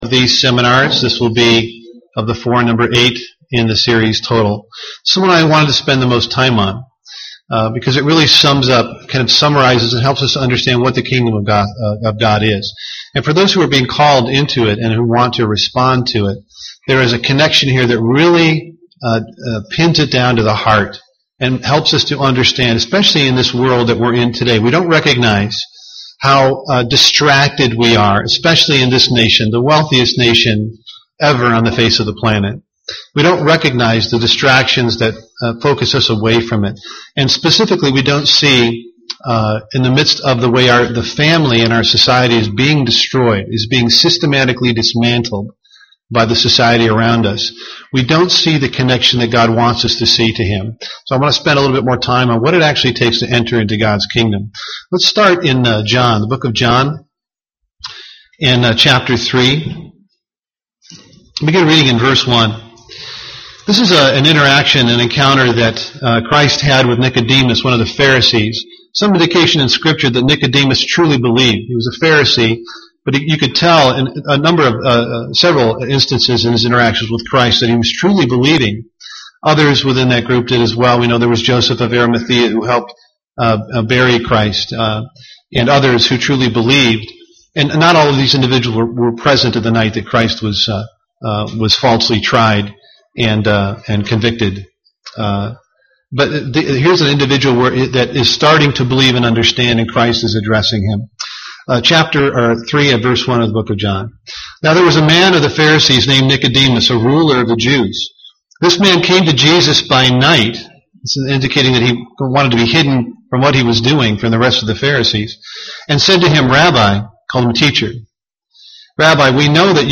How do those who respond to the Gospel change the way they live and what is their reward for doing so? Learn more in this Kingdom of God seminar.
UCG Sermon Studying the bible?